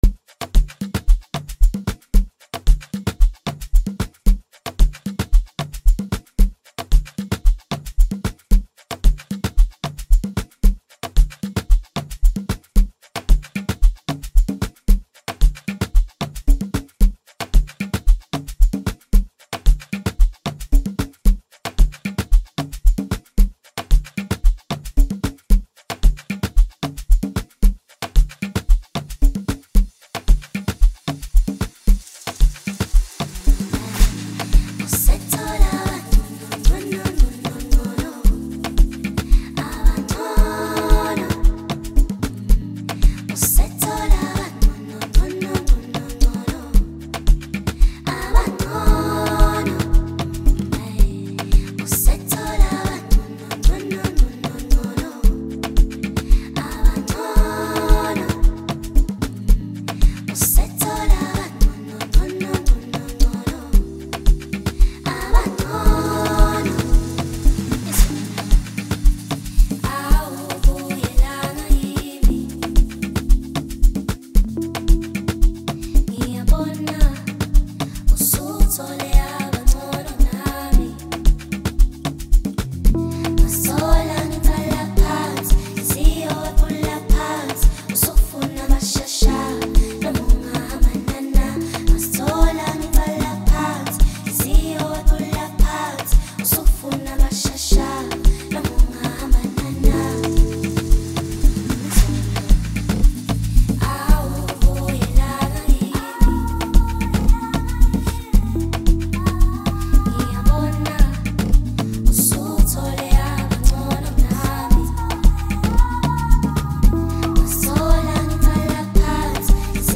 Home » Amapiano » DJ Mix » Hip Hop
elegantly arranged piece